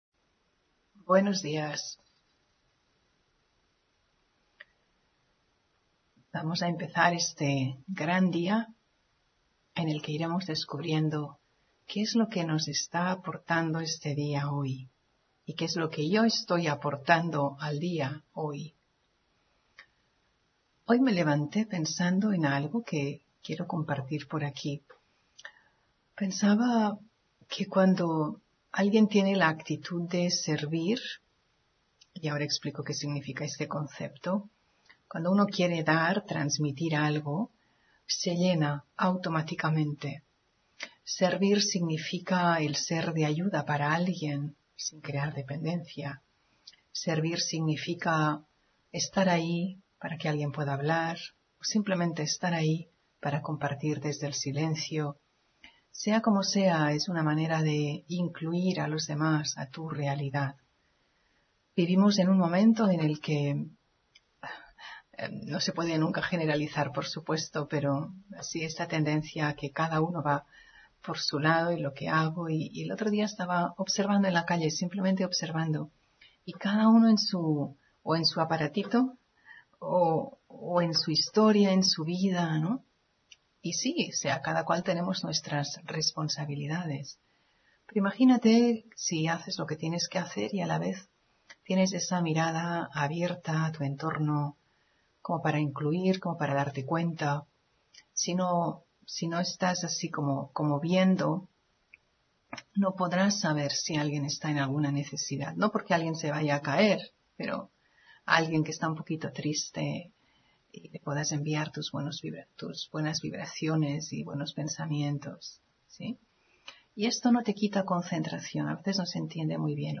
Meditación y conferencia: Meditar para lo que pueda suceder de repente ( 7 Mayo 2025)